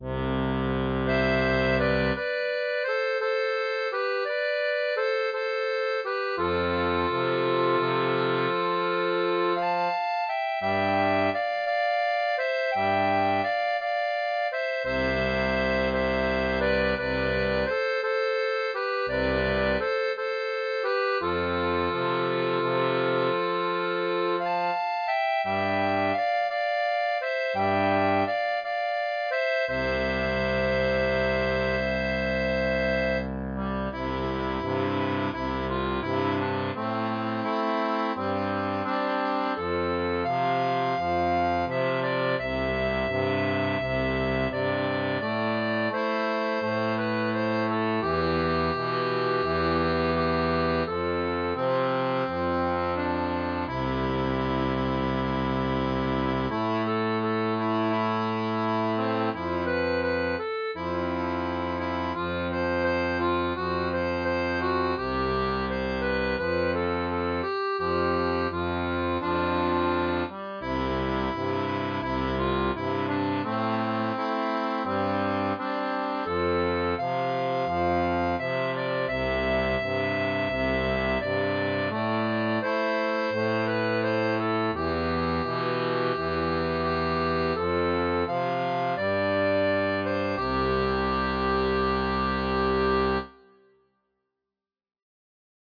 Type d'accordéon